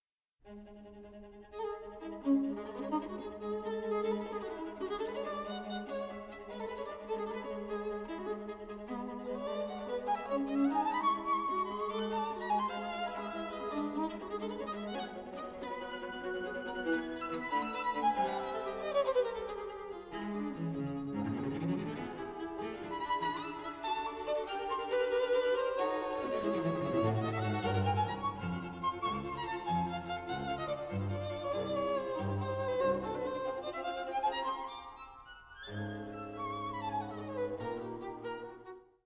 24 bit digital recording
violin
viola
cello